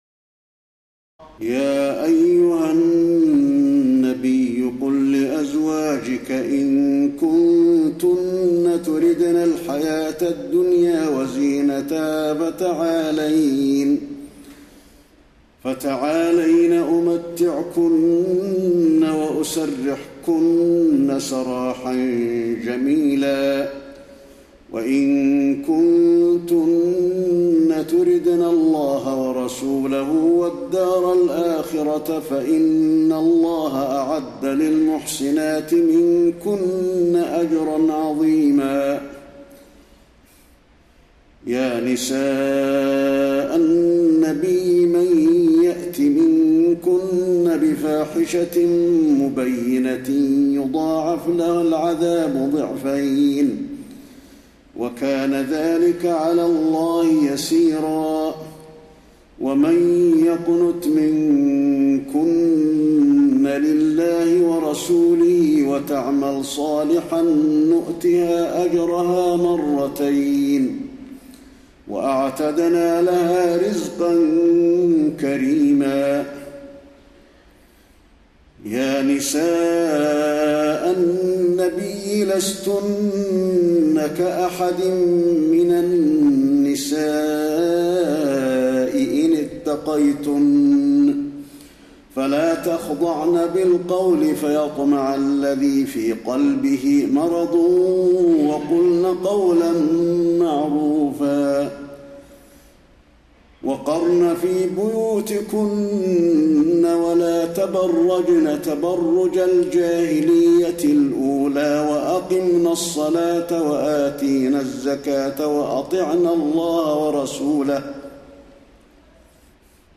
تراويح ليلة 21 رمضان 1432هـ من سور الأحزاب (28-73) وسبأ (1-23) Taraweeh 21 st night Ramadan 1432H from Surah Al-Ahzaab and Saba > تراويح الحرم النبوي عام 1432 🕌 > التراويح - تلاوات الحرمين